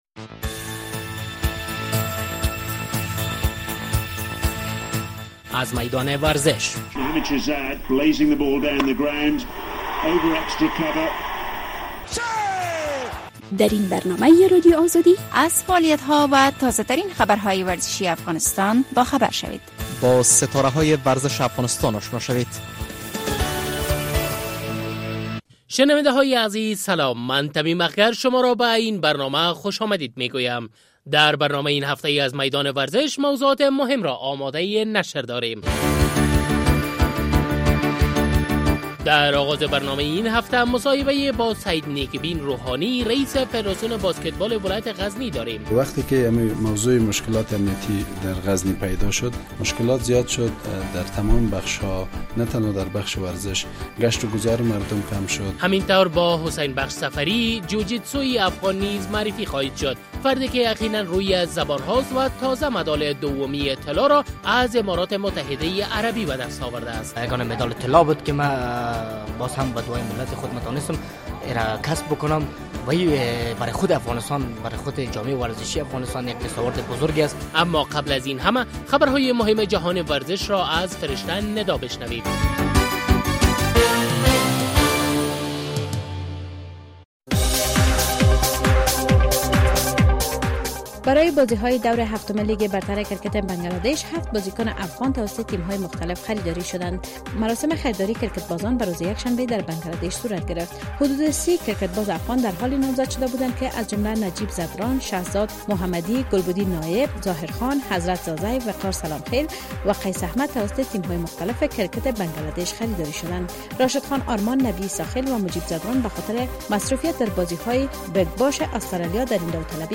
در آغاز برنامه این هفته مصاحبه